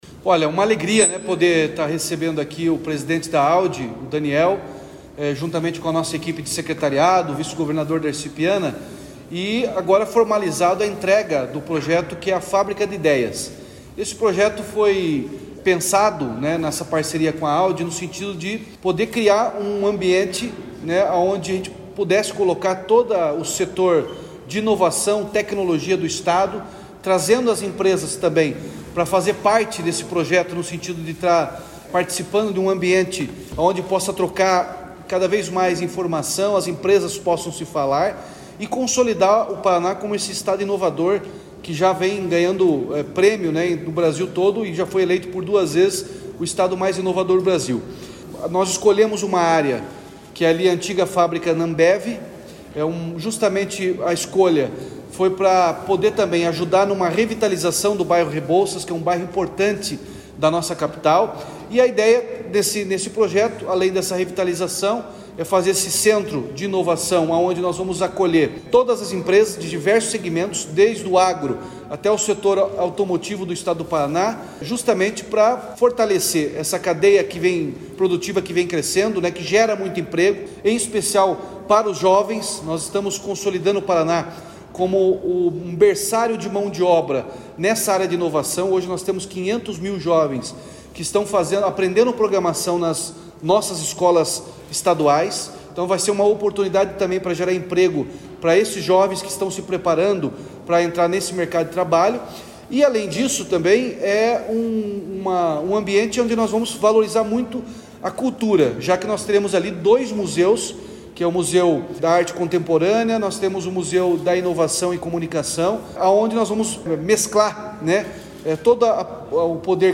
Sonora do governador Ratinho Junior sobre o projeto da Fábrica de Ideias, novo centro de inovação em Curitiba